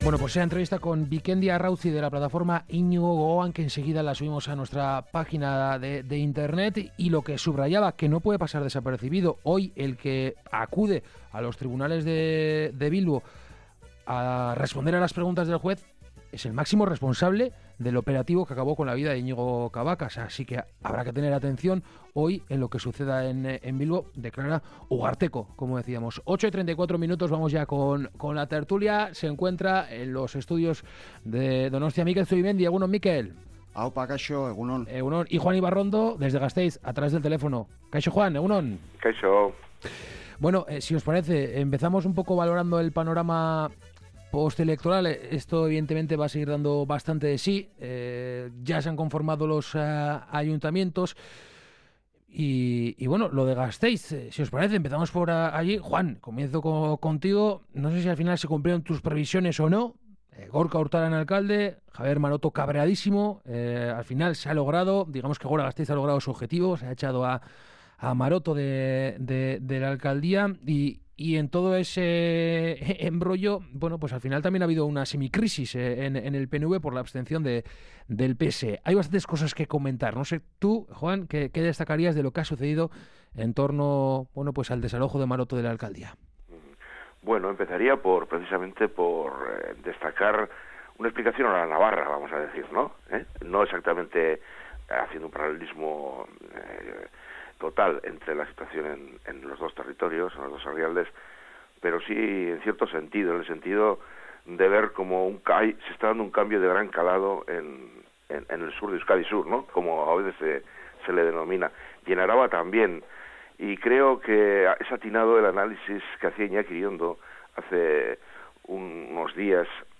Tertulia: Nafarroa eta Gure Esku Dago